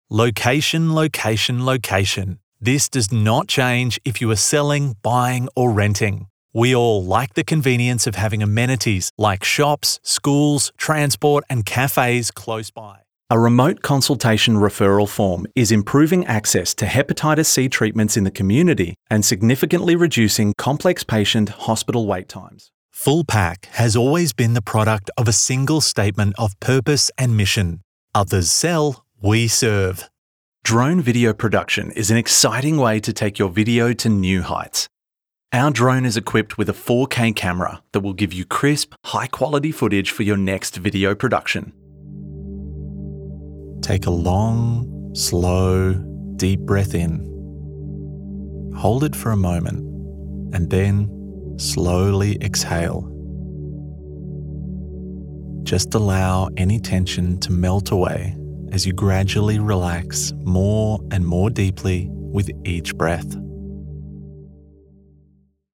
外籍男111-商务.mp3